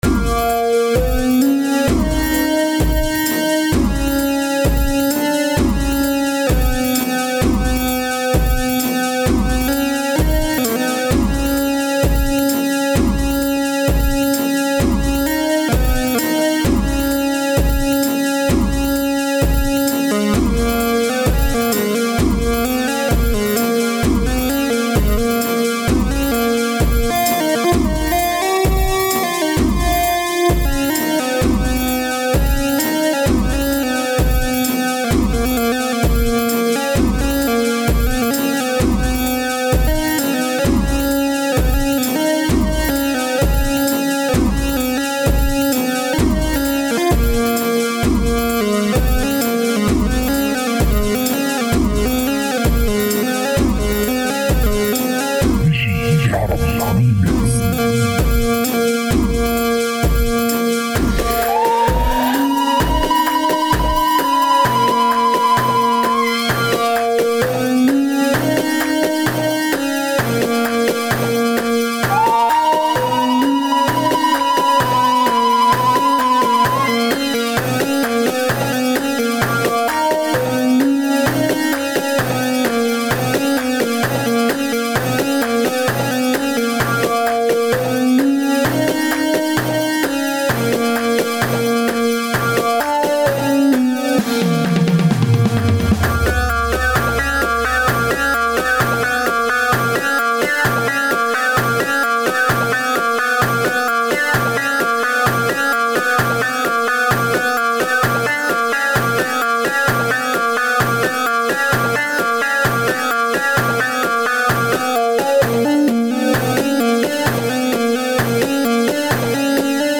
دبكه